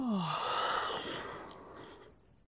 sigh1.wav